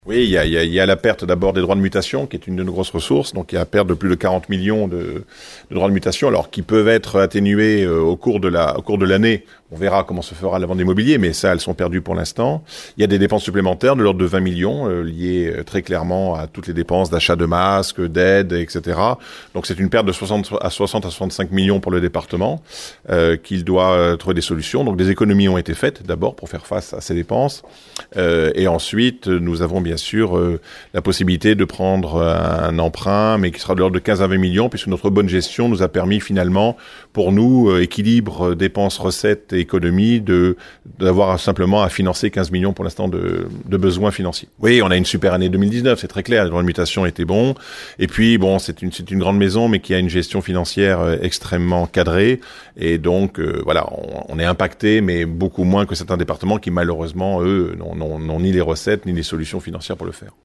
L’impact du covid-19 sur les finances de la collectivité a été détaillé hier en conférence de presse à La Rochelle par le président Dominique Bussereau et son vice-président Lionel Quillet. Ce dernier s’est employé à expliquer comment, grâce à une gestion maîtrisée du budget, le Département pourra finalement s’en sortir sans trop de dommages.